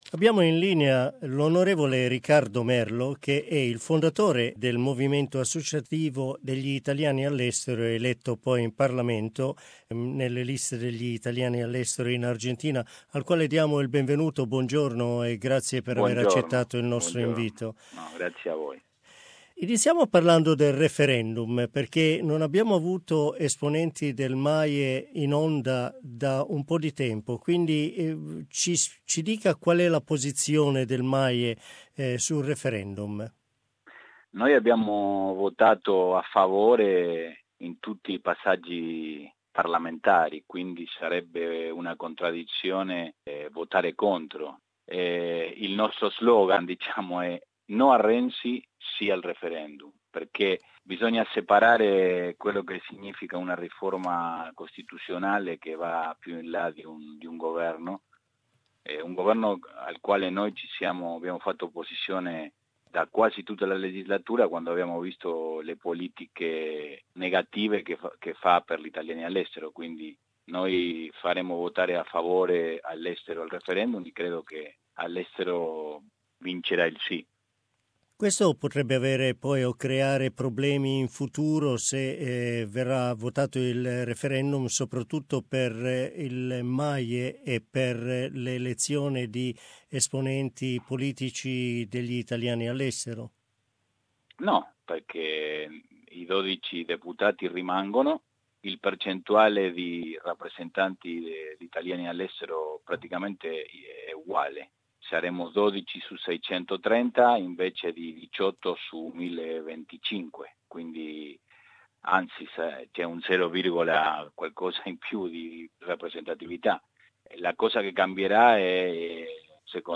Argentinian born MP Ricardo Merlo founded the Movimento Associativo Italiani all'Estero (MAIE) in 2007 making it a considerable political force for Italians abroad. In this interview he talks about the most recent Italian political events.